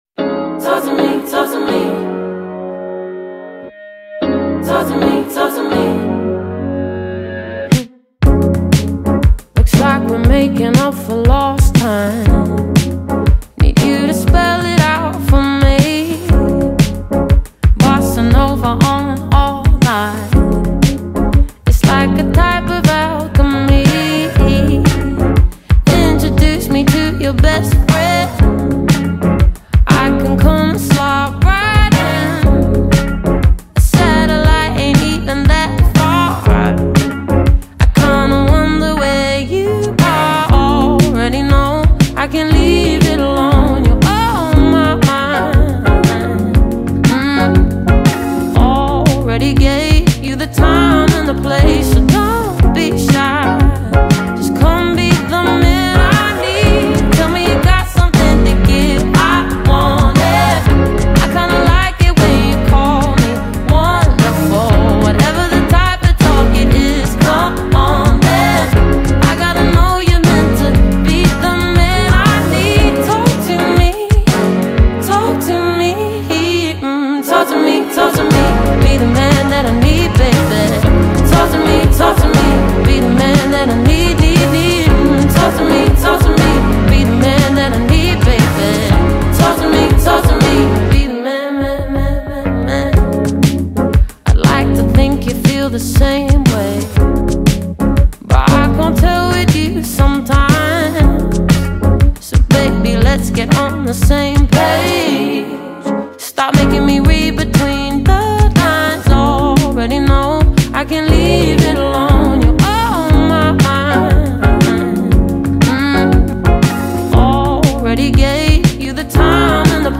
BPM119
Audio QualityPerfect (Low Quality)